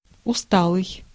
Ääntäminen
GenAm: IPA : /taɪɚd/ RP : IPA : /taɪəd/